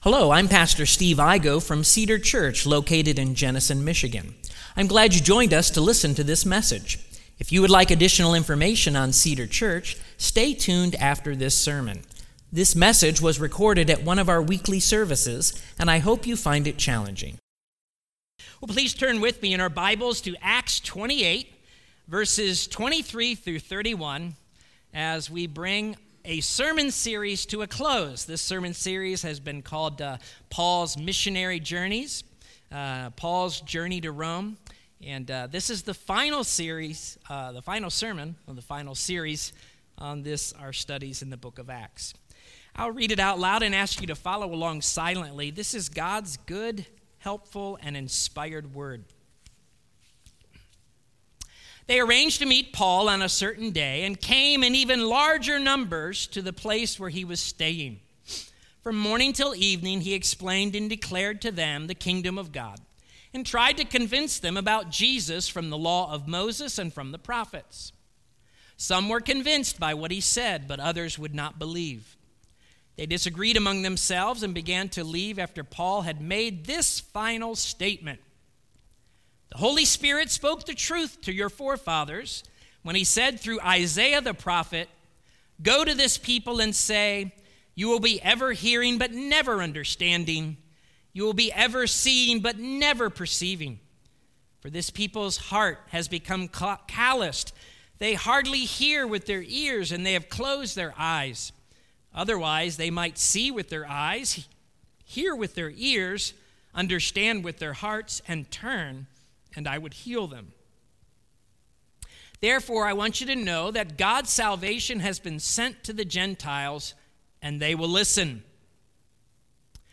Sermons | Cedar Church